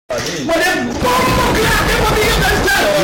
Bass Boosted Bomboclat Sound Button - Free Download & Play